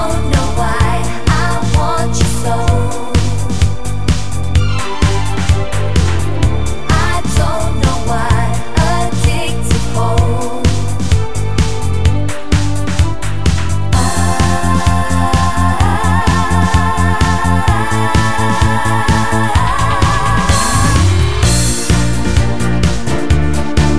Four Semitones Up Pop (2000s) 3:13 Buy £1.50